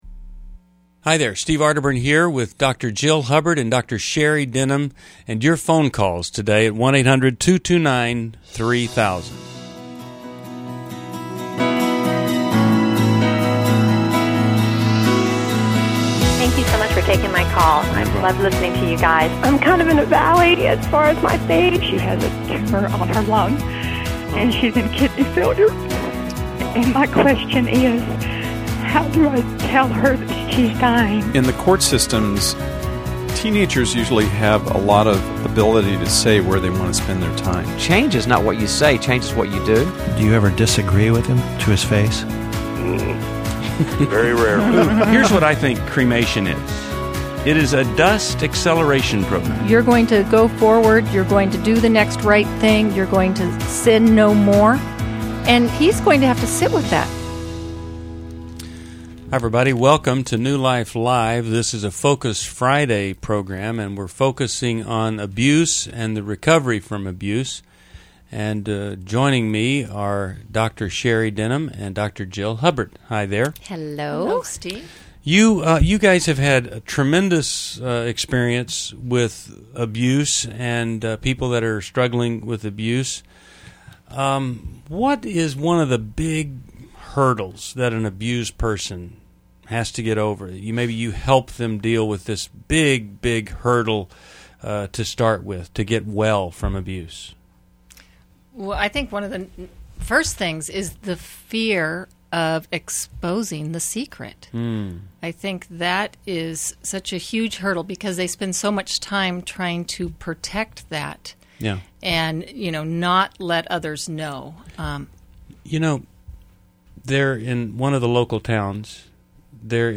New Life Live: December 2, 2011 delves into healing from sexual and physical abuse, featuring callers discussing recovery, family dynamics, and dating challenges.